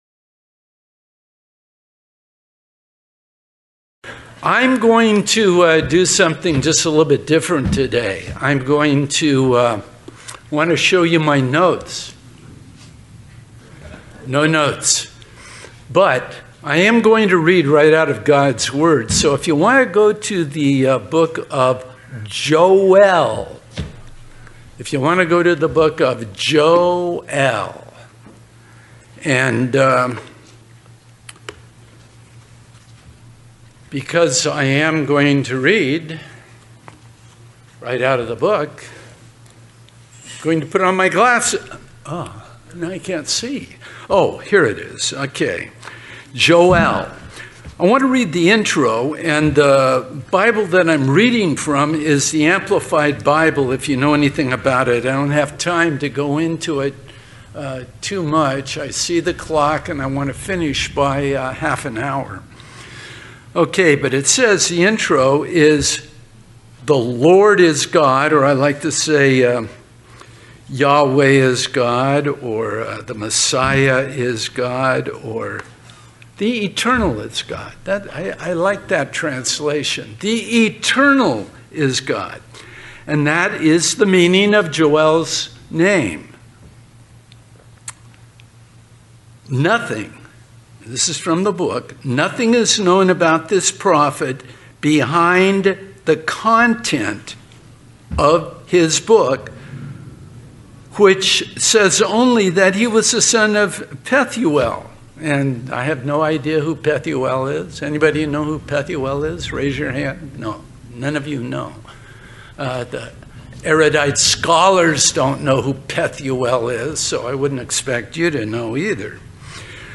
An expository message on the book of Joel that serves as a pre-Trumpets warning for God's church.